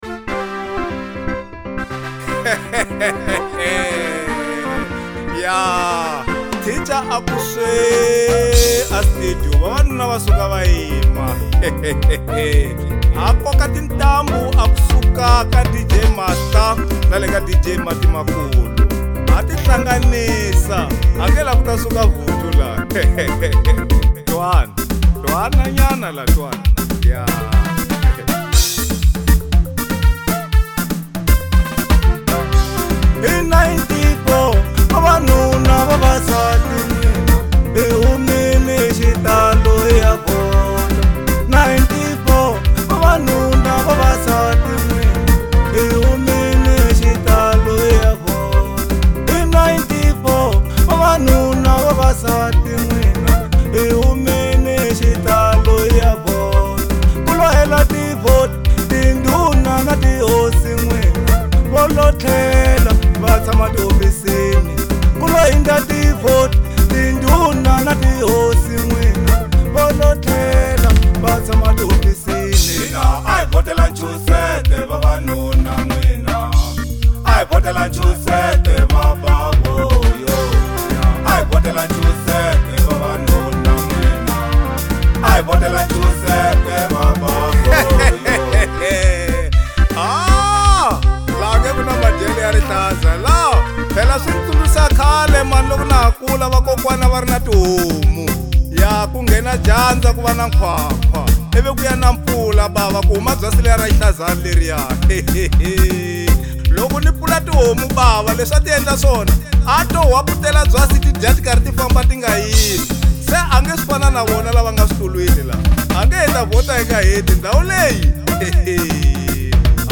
Genre : African Disco